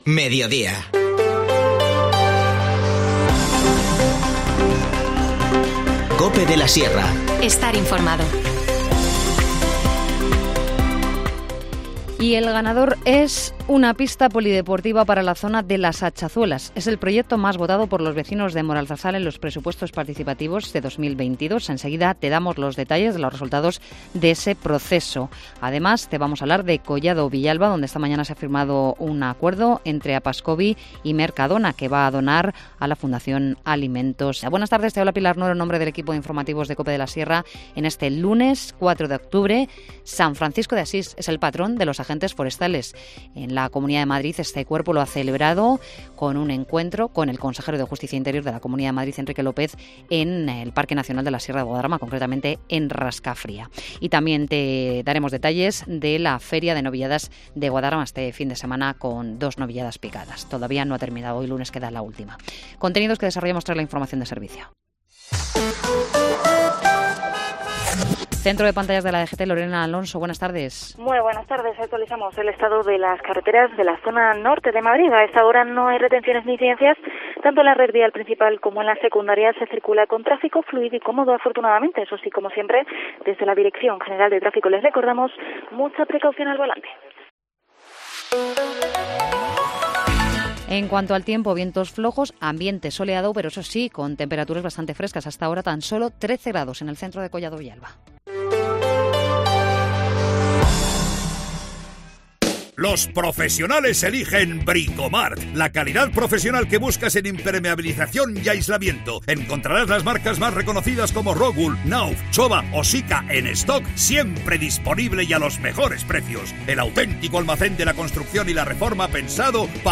Informativo Mediodía 4 octubre